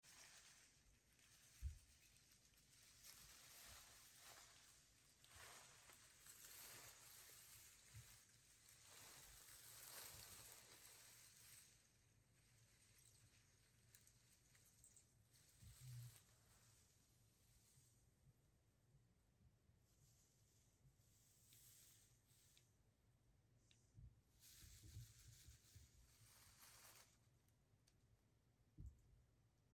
Sound design and foley for sound effects free download